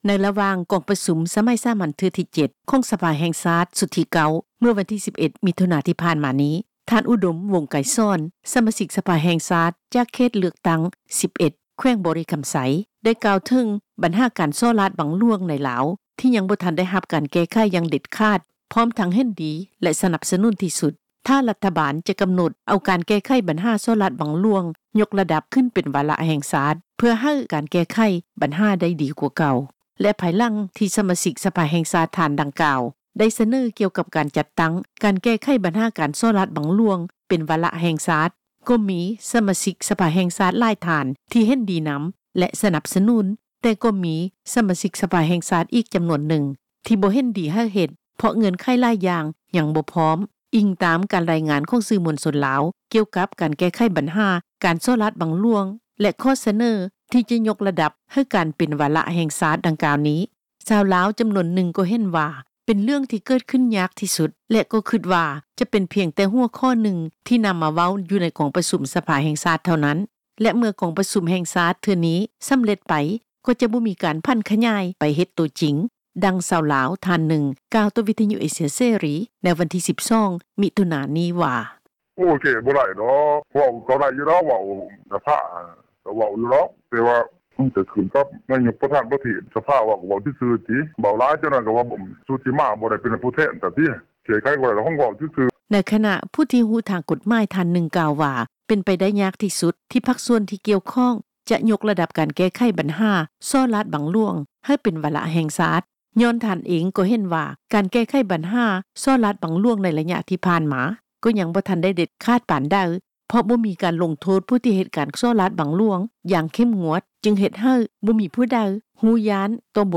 ດັ່ງຊາວລາວ ທ່ານໜຶ່ງ ກ່າວຕໍ່ ວິທຍຸເອເຊັຽເສຣີ ໃນວັນທີ 12 ມີຖຸນາ ນີ້ວ່າ